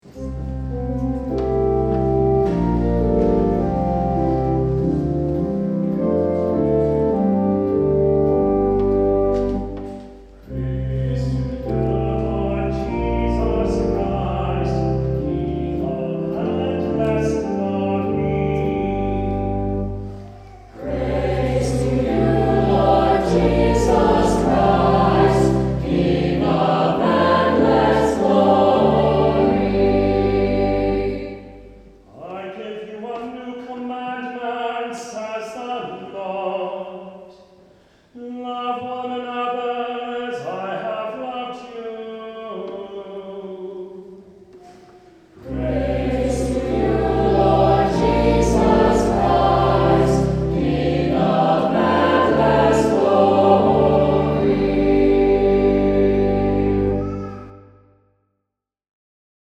Saint Clement Choir Sang this Song